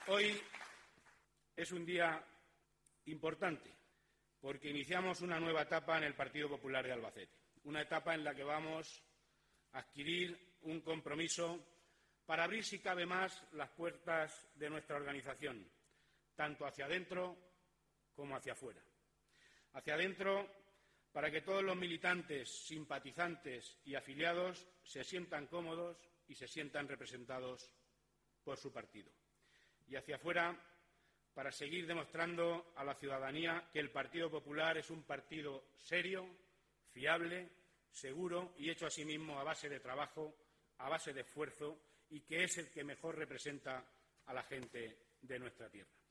El presidente provincial del Partido Popular de Albacete, Manuel Serrano, tras su proclamación con el 99,08 por ciento de los votos emitidos, ha agradecido la confianza depositada en su persona y en el “gran proyecto común” que lidera durante la clausura del XIII Congreso Provincial, celebrado bajo el lema ‘Más Albacete, más España’ y con la presencia del presidente del Partido Popular de Castilla-La Mancha, Paco Núñez, y del secretario general del Partido Popular nacional, Teodoro García Egea.
cortevoz.manuelserranosecomprometea.mp3